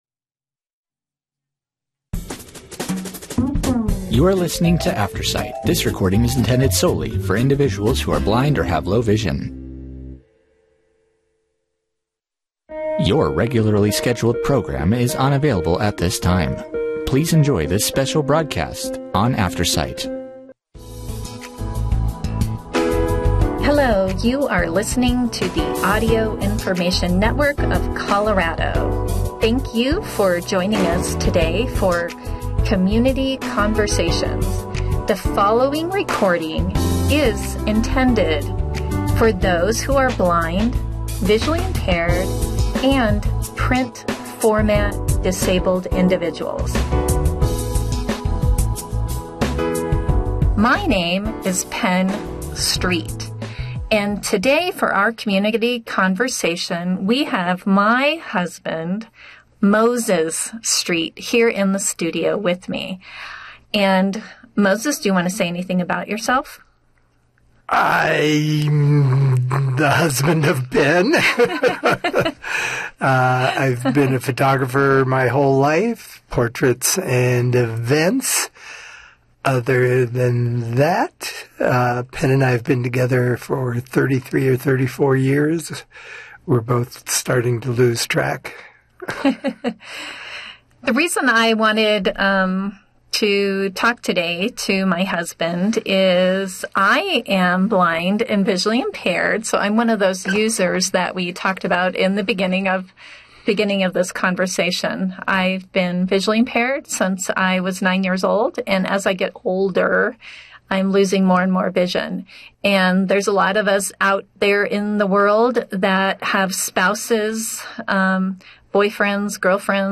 Play Rate Listened List Bookmark Get this podcast via API From The Podcast Safeway weekly ad in audio format.